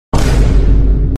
Vine Boom Sound Effect Free Download